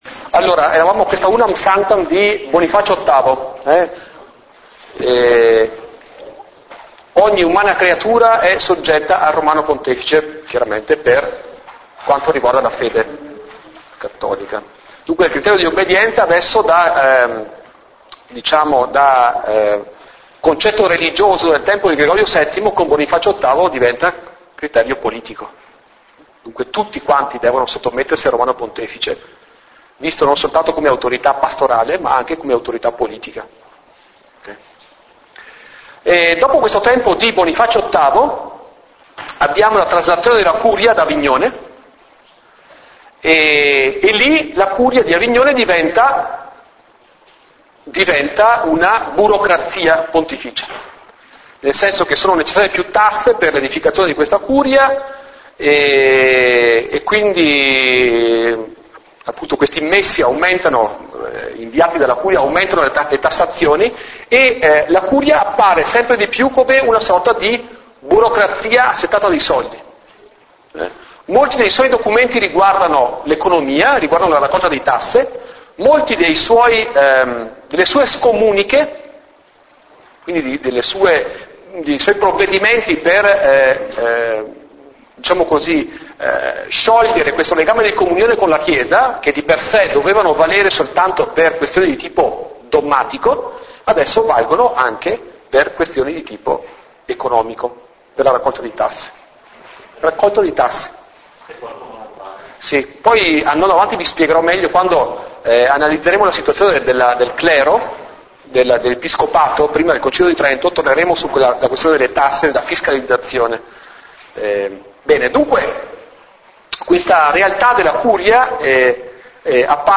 In Italian: 05� lezione registrata: "Origini della Riforma"